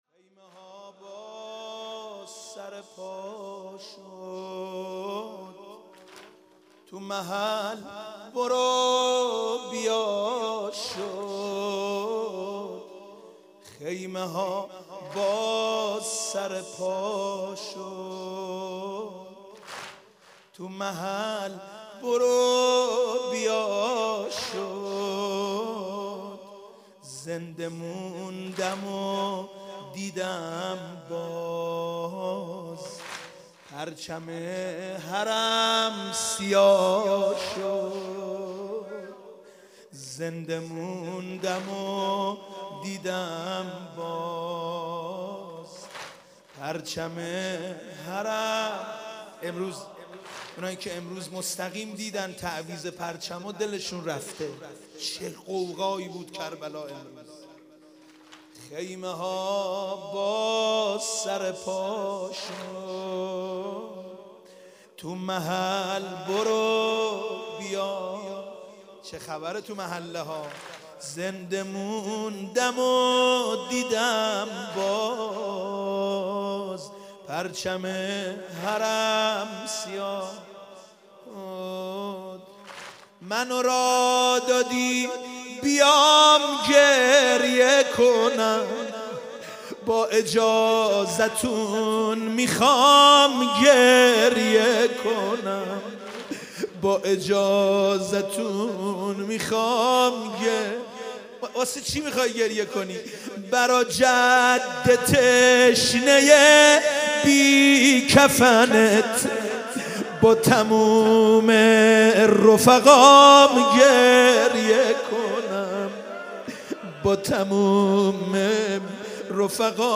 شب اول محرم سال 95/هیت رزمندگان اسلام